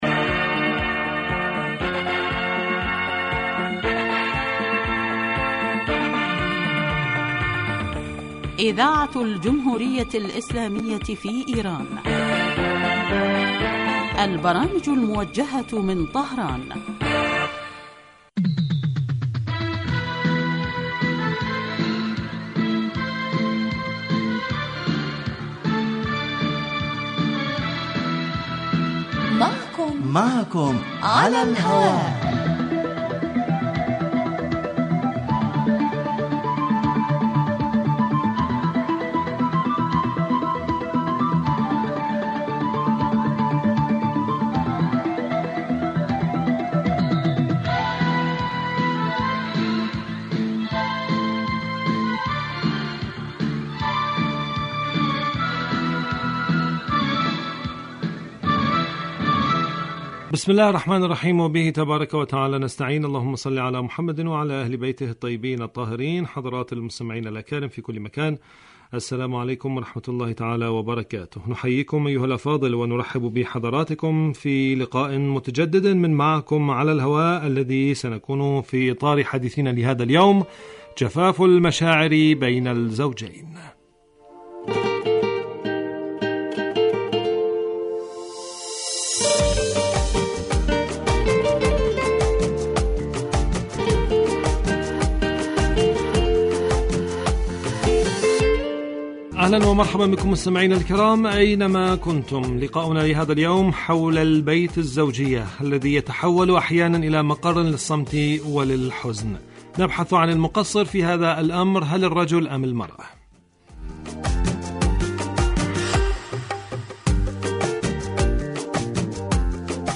من البرامج المعنیة بتحلیل القضایا الاجتماعیة في دنیا الإسلام و العرب و من أنجحها الذي یلحظ الکثیر من سیاسات القسم الاجتماعي بصورة مباشرة علی الهواء وعبر الاستفادة من رؤی الخبراء بشان مواضیع تخص هاجس المستمعین